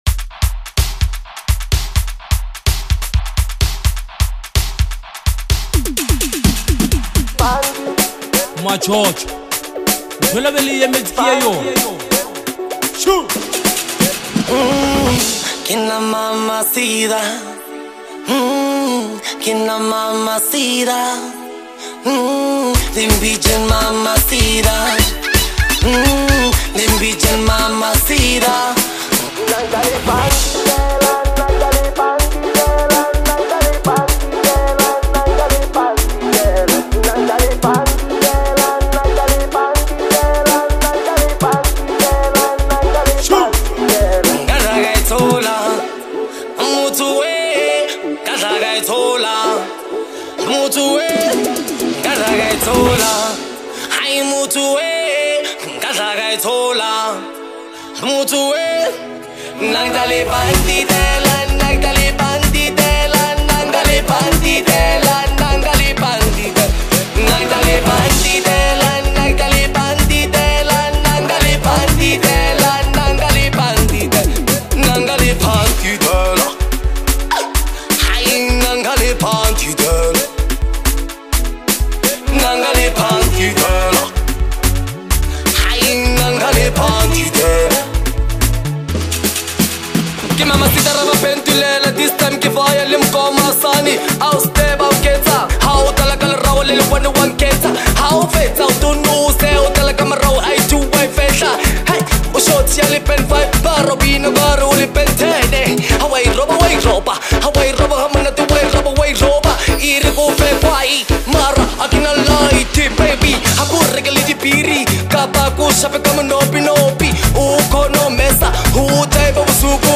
is a raw, emotional song
production sets a tense and emotionally charged atmosphere